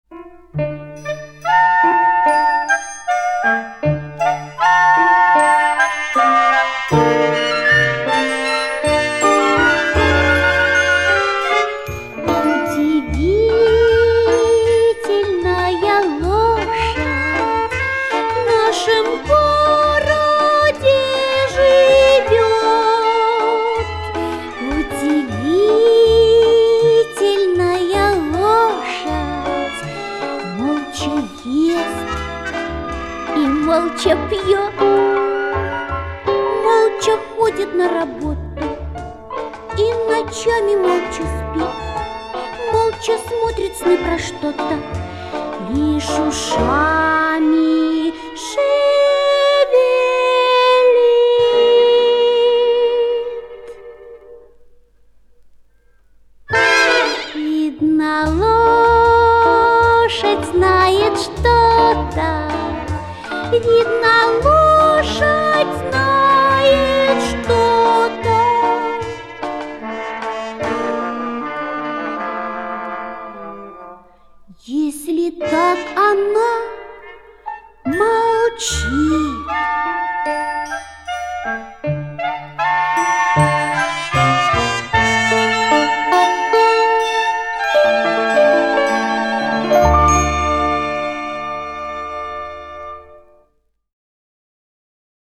Главная / Песни для детей / Песни про животных